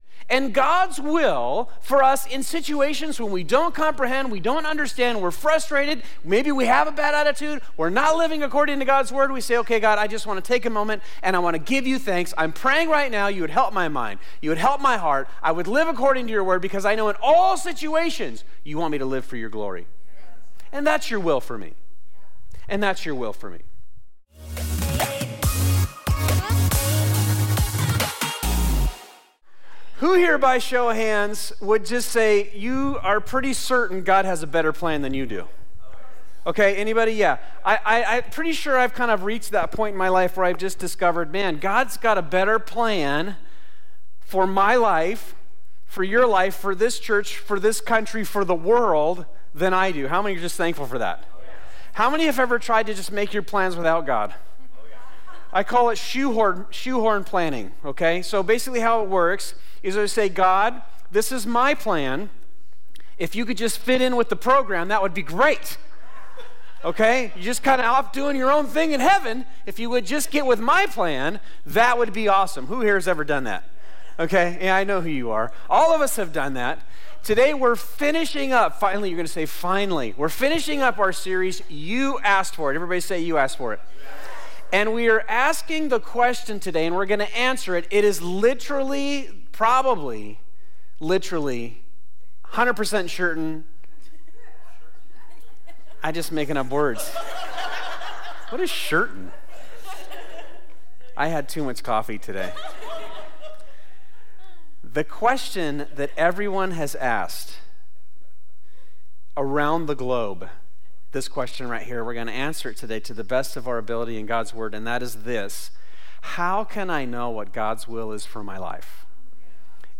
This is Part 7 of "You Asked For It," our sermon series at Fusion Christian Church where we answer real questions from members of our church community. We look to give biblical answers for the questions people have and the problems people face.